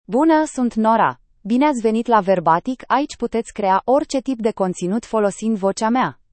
NoraFemale Romanian AI voice
Nora is a female AI voice for Romanian (Romania).
Voice sample
Female
Nora delivers clear pronunciation with authentic Romania Romanian intonation, making your content sound professionally produced.